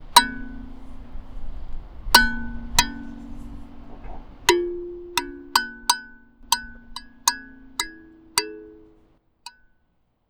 우주_칼림바2.wav